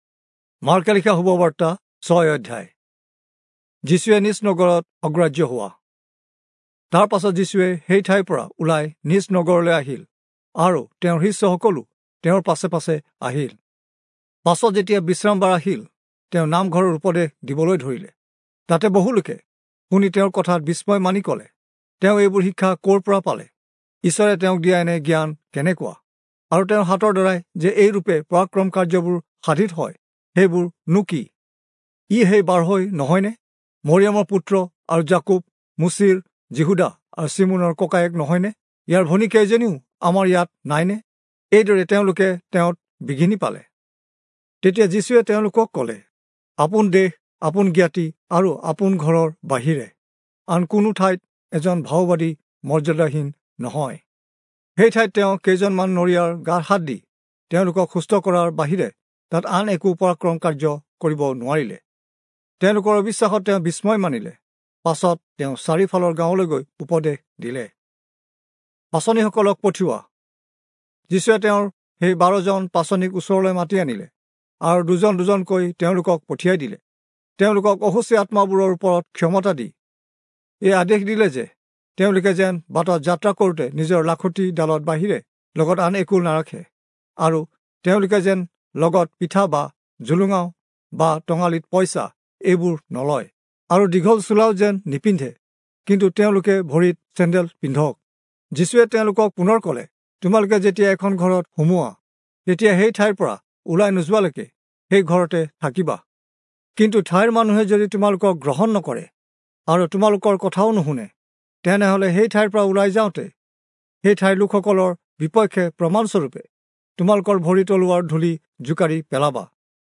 Assamese Audio Bible - Mark 15 in Alep bible version